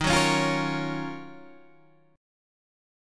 D# Minor 9th